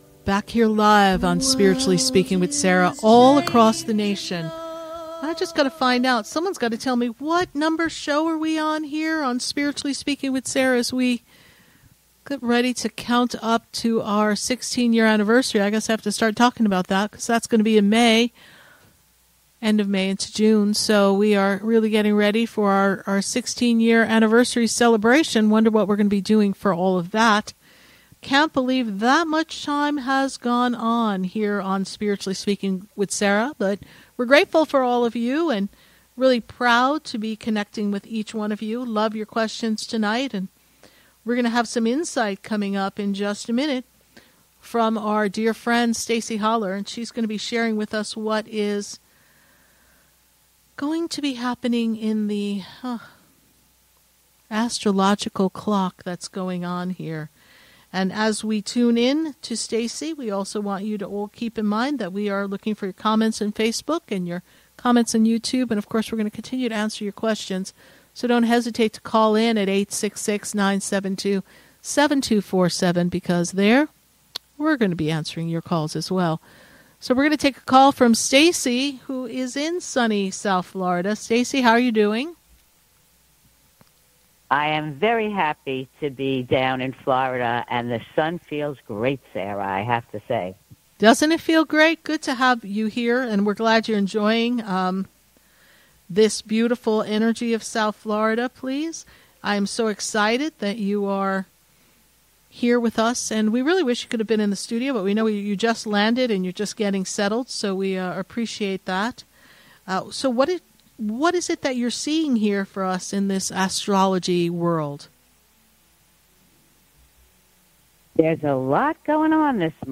LIVE on the radio